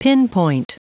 Prononciation du mot pinpoint en anglais (fichier audio)
Prononciation du mot : pinpoint